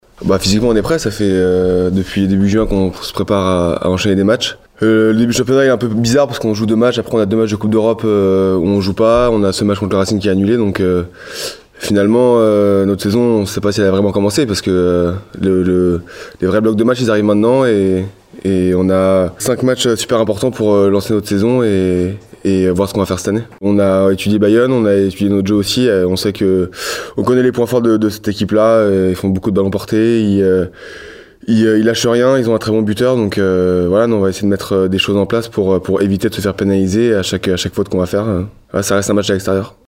Rugby / Top 14 : Bayonne/La Rochelle ce soir à 20h45 (J. Plisson en ITV) – HELENE FM
Les Jaune et noir ne partent pas favoris, mais ils vont tout donner pour ramener une victoire, comme le souligne l’ouvreur Jules Plissson :